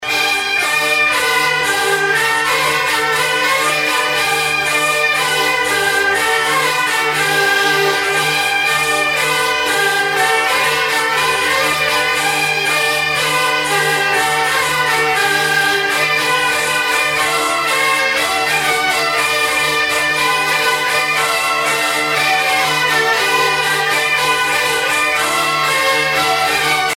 Fonction d'après l'analyste danse : bourree ;
Pièce musicale éditée